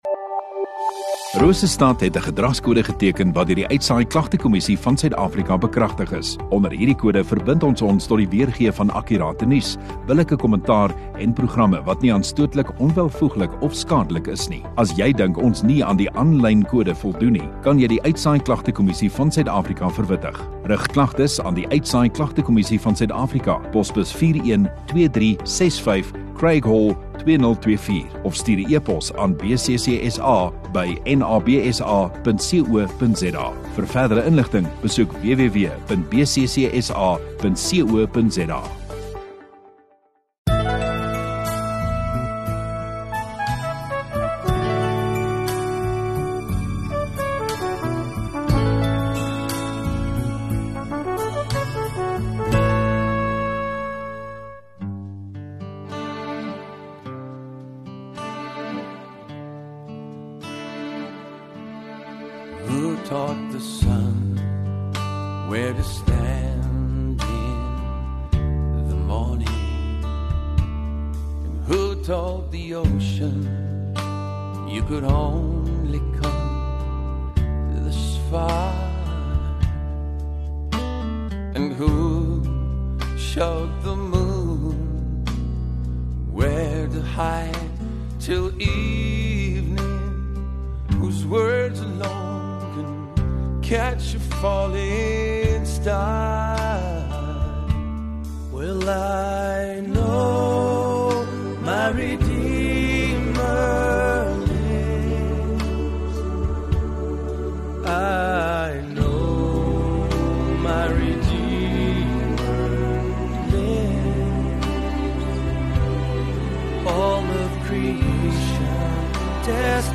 25 Aug Sondagoggend Erediens